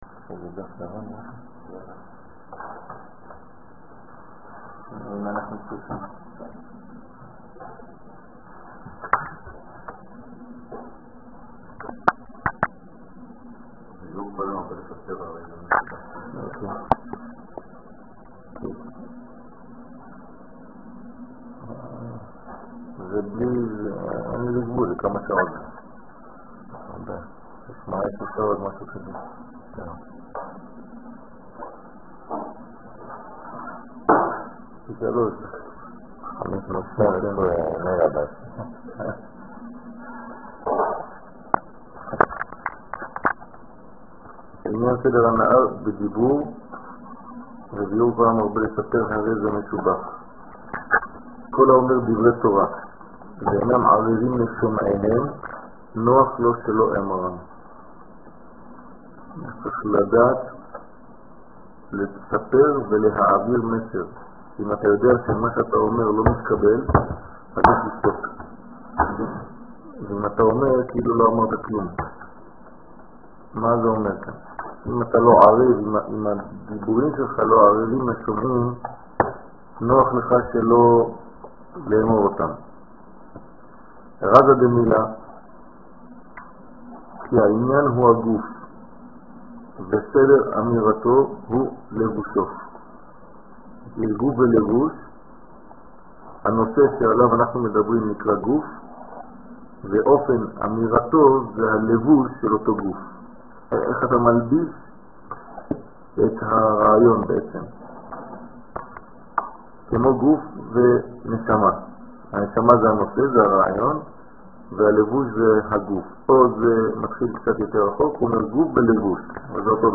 ערב פסח תשס''ה חגים ראש חודש וחודשי שיעור מ 28 אפריל 2005 02H 54MIN הורדה בקובץ אודיו MP3 (159.51 Mo) הורדה בקובץ אודיו M4A (21.79 Mo) TAGS : פסח תורה ומועדים עברית שיעורים תורה וזהות הישראלי שיעורים קצרים